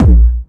GS Phat Kicks 005.wav